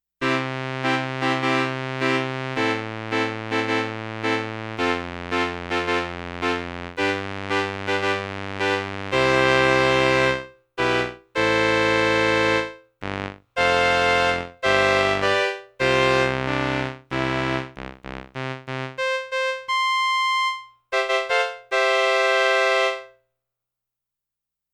The Roland RS-202 is a classic 1970s "string ensemble" keyboard, with a sound and and an ensemble effect similar to the classic Solina.
01 BRASS
01 BRASS.mp3